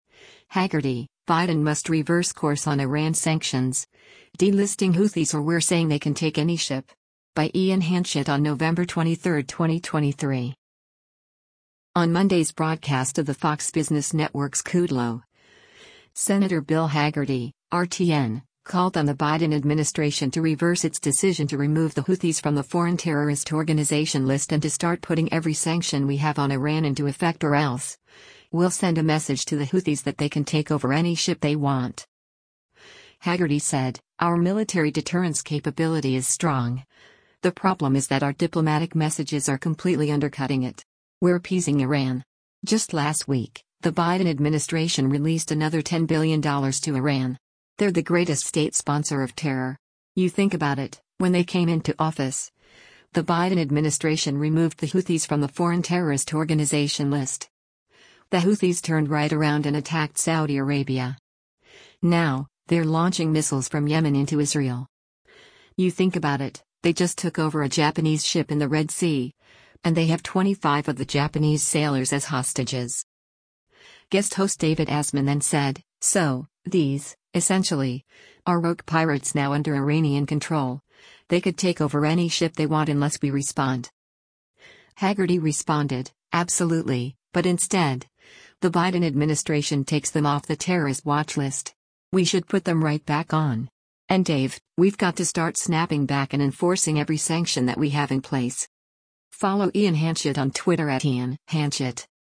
On Monday’s broadcast of the Fox Business Network’s “Kudlow,” Sen. Bill Hagerty (R-TN) called on the Biden administration to reverse its decision to remove the Houthis from the foreign terrorist organization list and to start putting every sanction we have on Iran into effect or else, we’ll send a message to the Houthis that they can take over any ship they want.
Guest host David Asman then said, “So, these, essentially, are rogue pirates now under Iranian control, they could take over any ship they want unless we respond.”